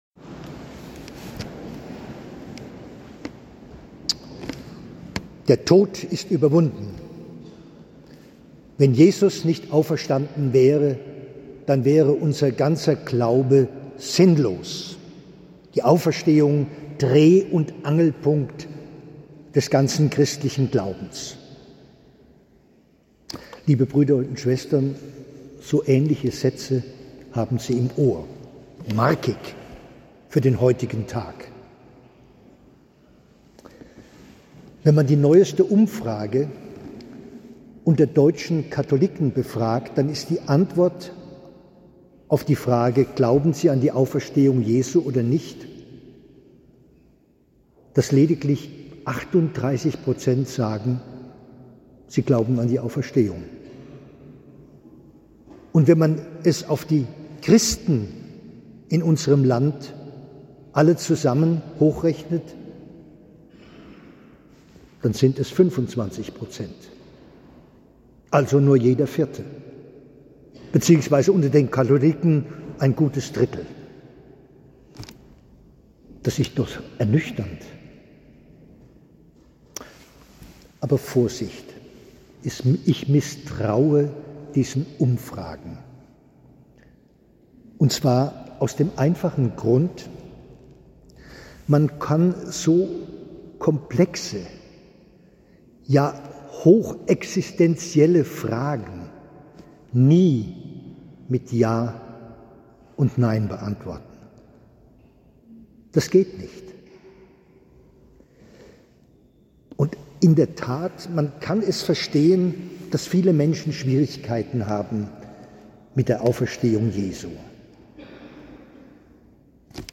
Predigt
Bürgersaalkirche